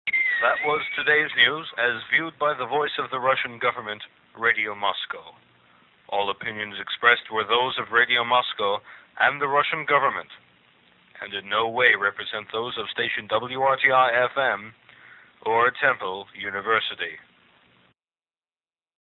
The end tag and disclaimer from "Radio Moscow."